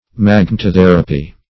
Search Result for " magnetotherapy" : The Collaborative International Dictionary of English v.0.48: Magnetotherapy \Mag`net*o*ther"a*py\, n. (Med.) The treatment of disease by the application of magnets to the surface of the body.